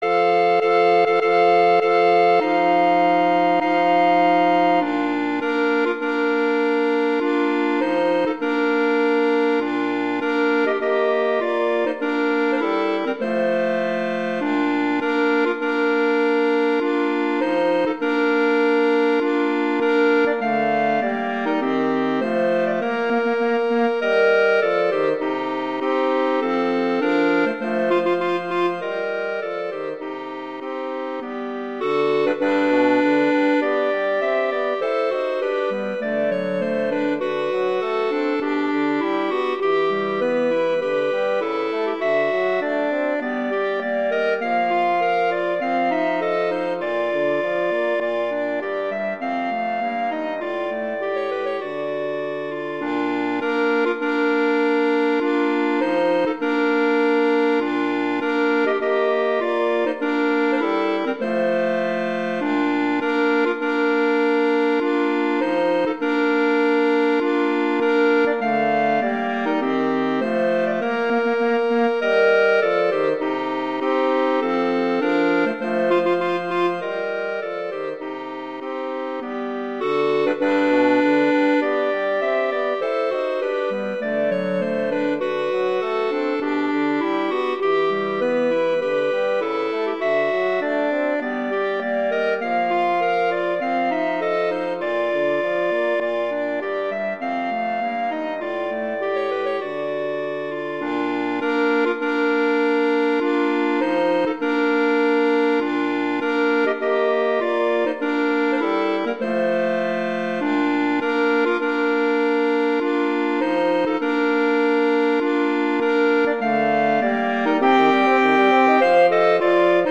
arrangements for two clarinets and bassoon
wedding, traditional, classical, festival, love, french